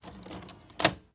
VHS_Eject.wav